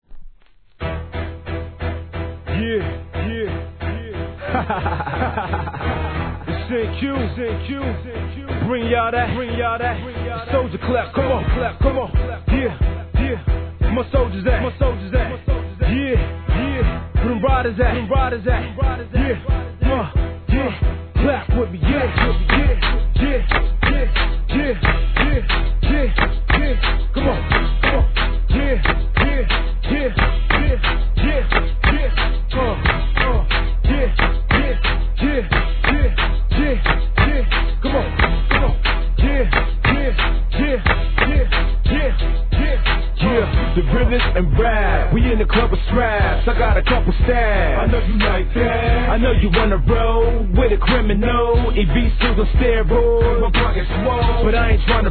G-RAP/WEST COAST/SOUTH
スリリングなクラップ・バウンス!!